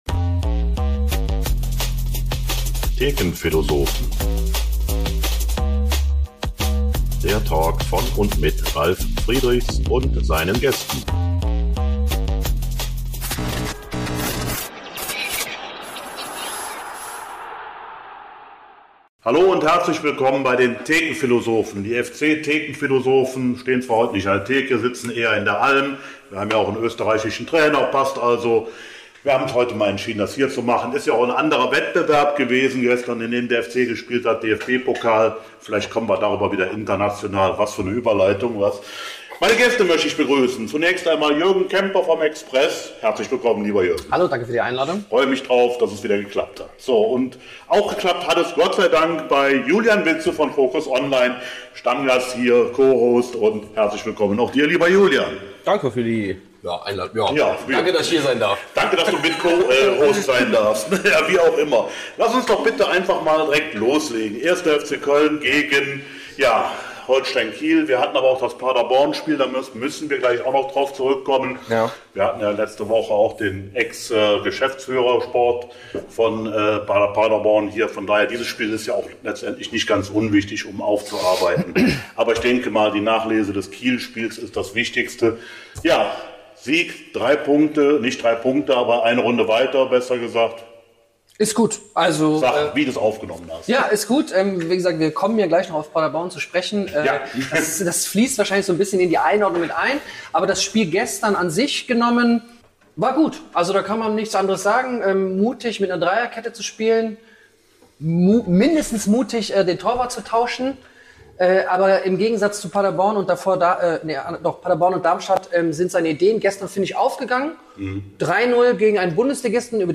1.FC Köln: 3:0-Sieg über Kiel & Pokal-Achtelfinale – In der Liga schlechter als unter Schuster - Folge 68 ~ FC-Thekenphilosophen - Der Talk Podcast